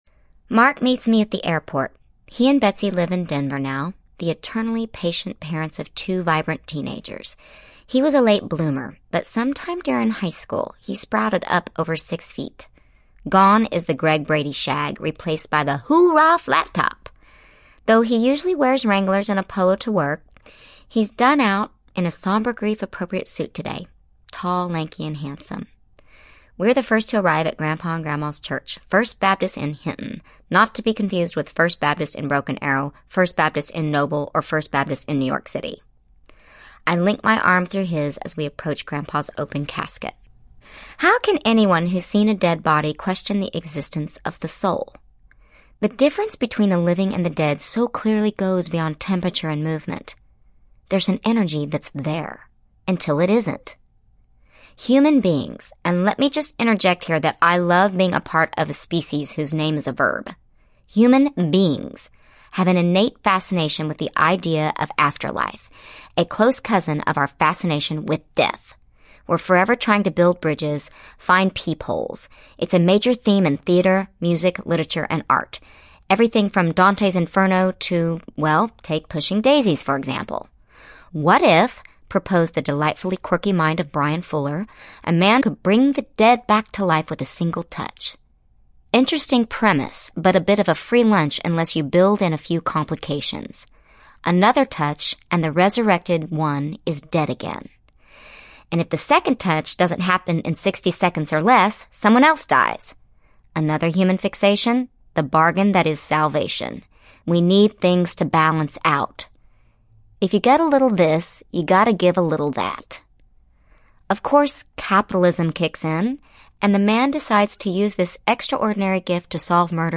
A Little Bit Wicked Audiobook
Narrator
Kristin Chenoweth
6.8 Hrs. – Unabridged